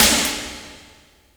REV. SNARE.wav